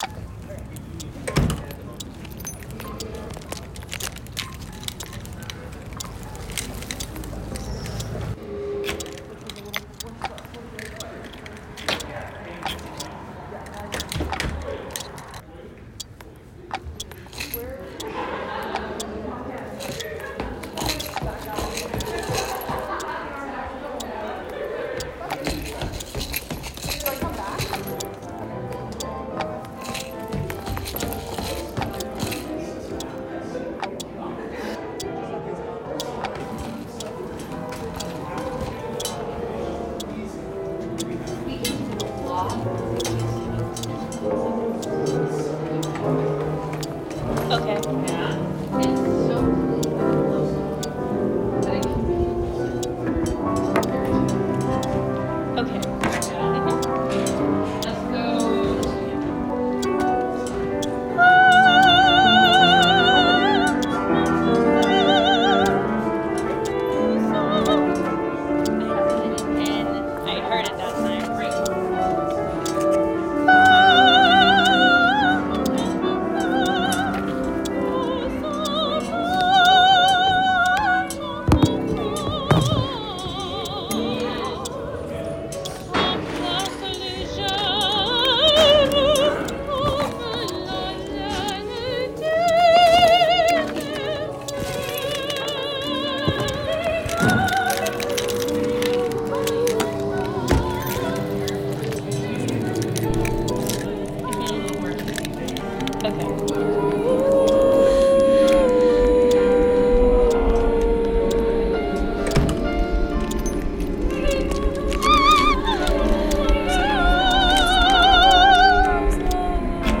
How can we represent the growth out of control digital addiction can feel like, beyond words? I've done my best to encapsulate that feeling of bring endlessly surrounded by information, with no way out, here.